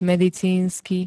medicínsky [-d-] -ka -ke príd.
Zvukové nahrávky niektorých slov